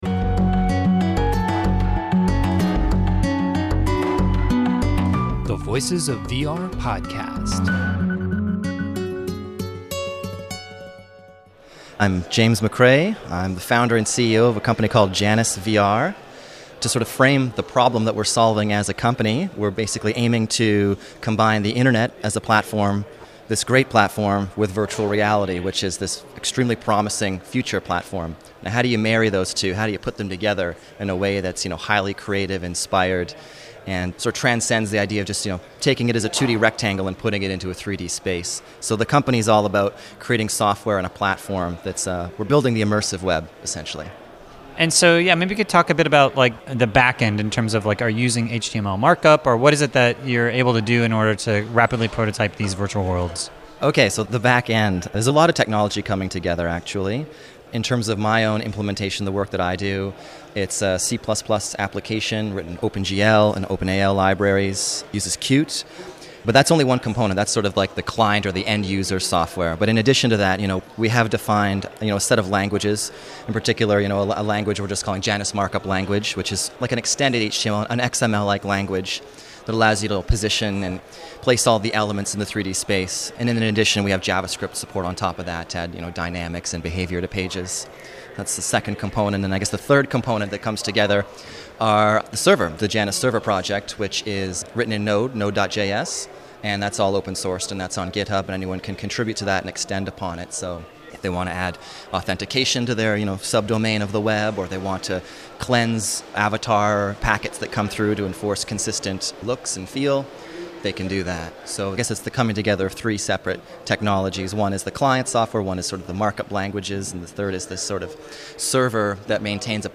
at the VRX Conference in San Francisco